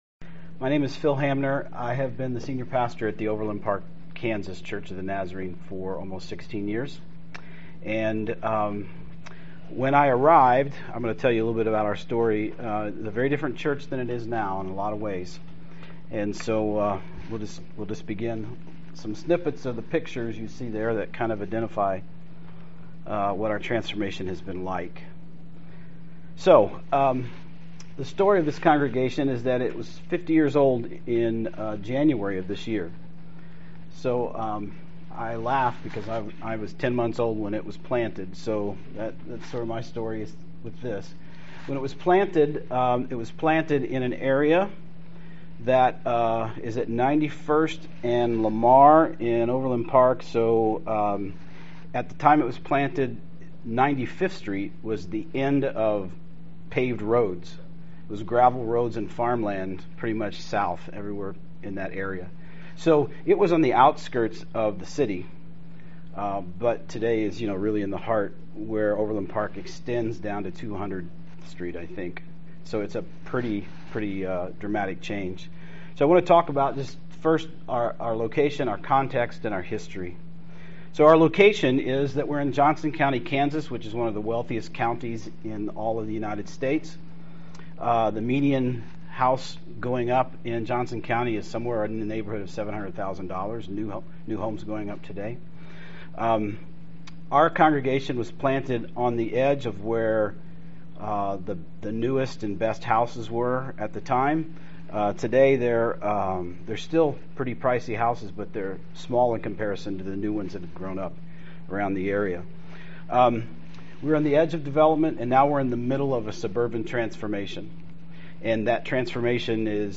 How do you determine where to start ministry to another cultural group — whether it is a group that is ethnically or racially different from yourself or a congregation whose cultural world is radically different from yours? This NTS-sponsored workshop offers a pattern of asking questions and listening beneath the surface to understand the people to whom and with whom you minister.